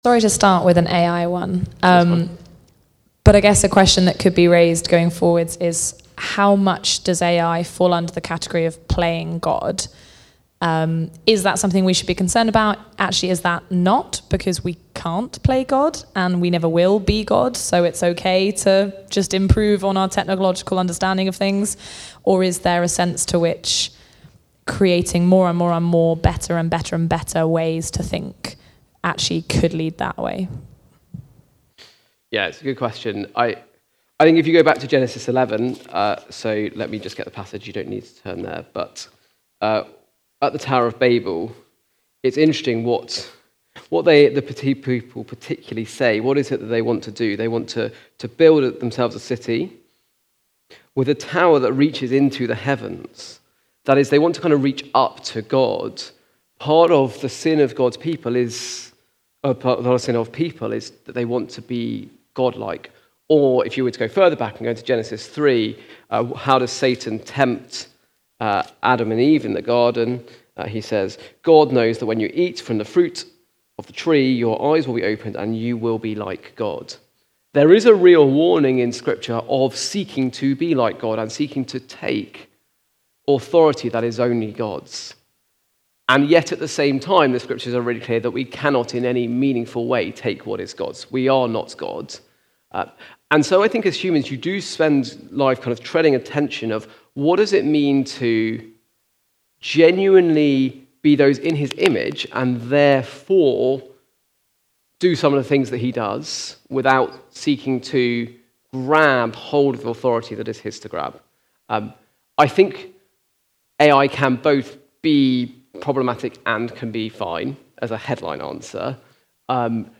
Preaching
Technology: Q And A Session from the series Following Christ in a Digital World. Recorded at Woodstock Road Baptist Church on 16 November 2025.